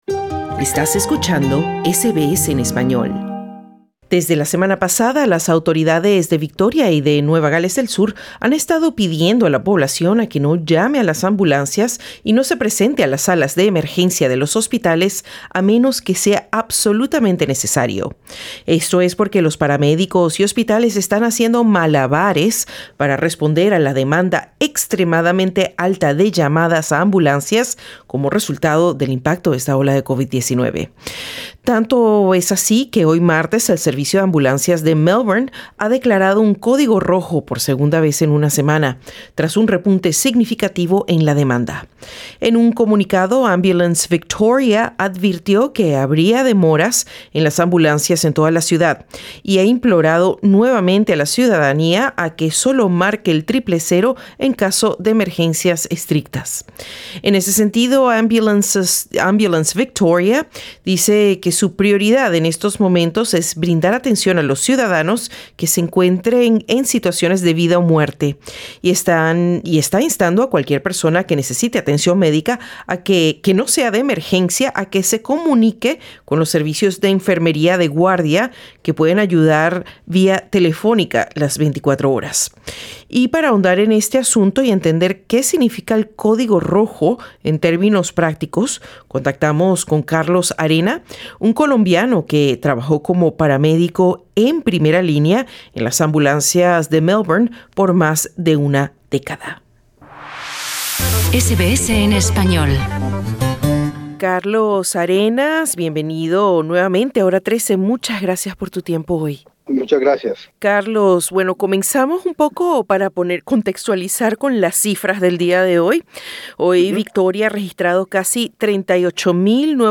Los servicios de emergencia de Australia están experimentando una alta demanda debido al aumento de casos de COVID-19 en el país. Un ex paramédico colombiano, que trabajó por más de una década en el estado de Victoria, explica a SBS Spanish en qué consiste el Código Rojo y en qué casos se justifica llamar una ambulancia por una emergencia.